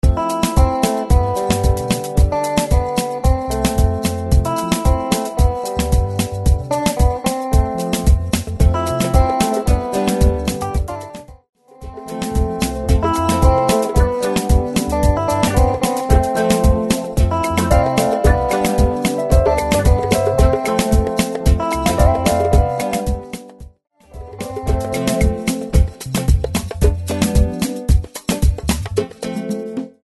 112 BPM
Upbeat World